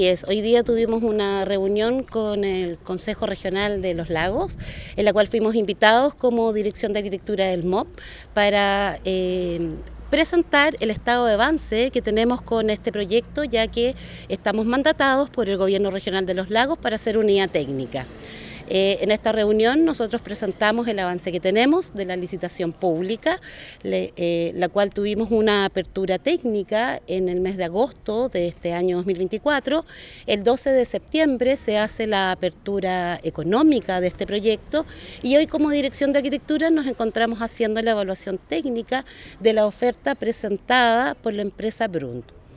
Según explicó, Alexandra Stepankowsky, Jefa de la Dirección de Arquitectura del MOP, esta visita se enmarca en dar a conocer los avances que se han dado con el proyecto del Liceo Carmela Carvajal, donde una empresa se encuentra preseleccionada para licitar.